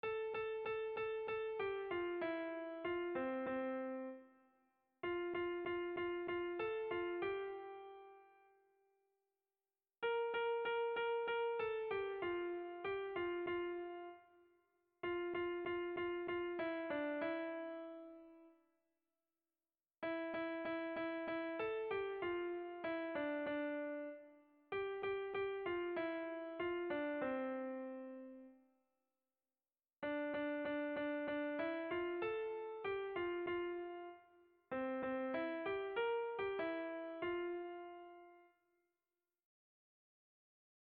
Zortziko handia (hg) / Lau puntuko handia (ip)
A1A2BD